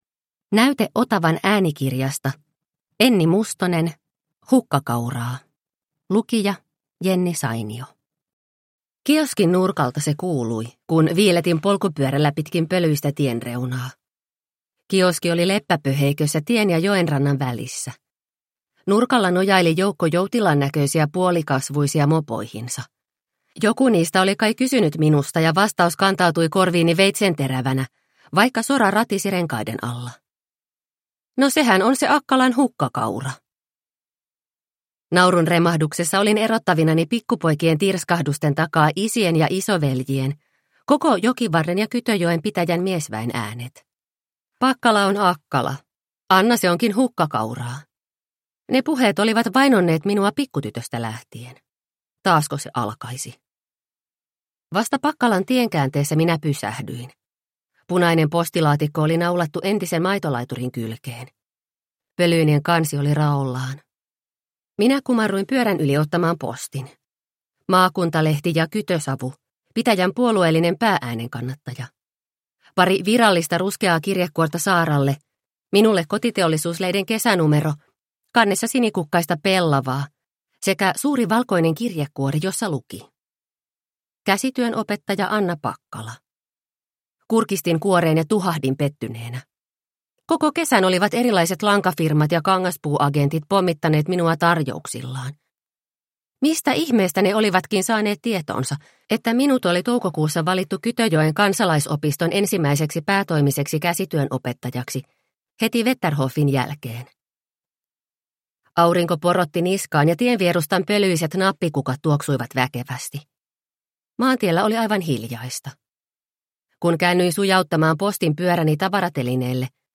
Hukkakauraa – Ljudbok – Laddas ner